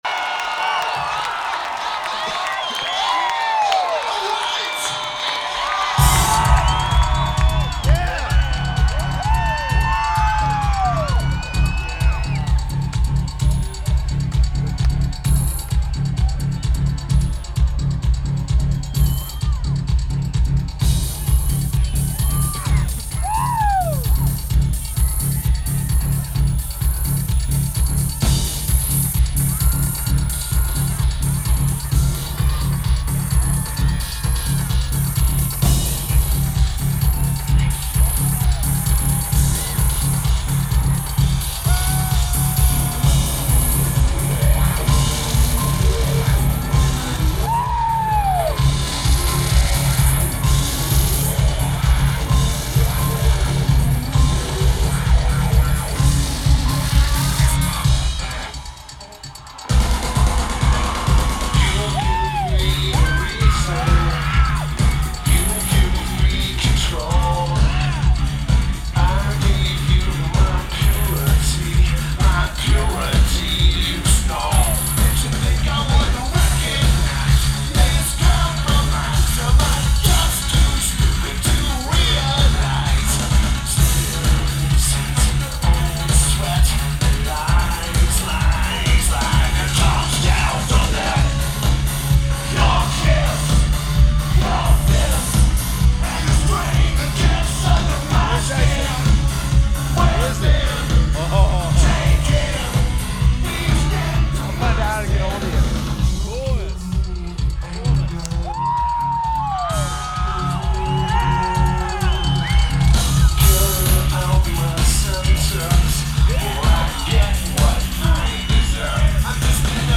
Red Rocks Amphitheatre
Lineage: Audio - AUD (DPA 4061 + CSBB + JB3)
Notes: Good recording with screamers inbetween songs.